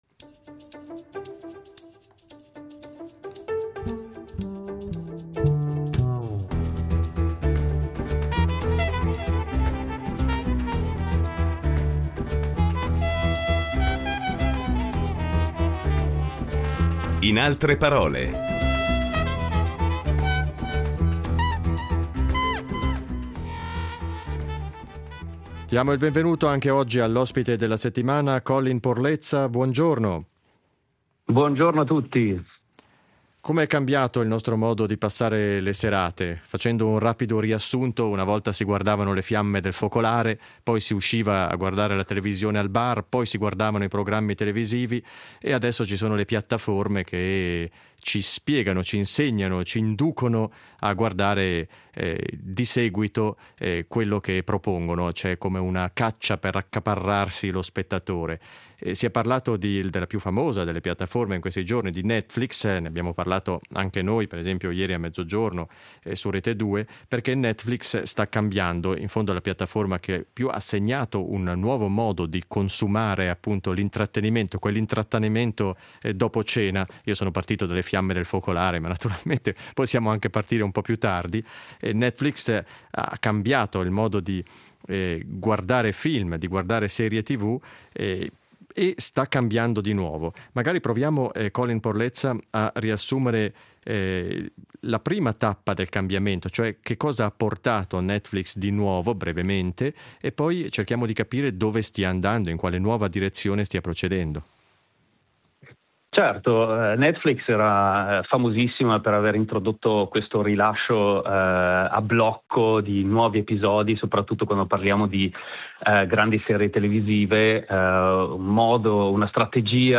Ospite della settimana il professore associato di giornalismo digitale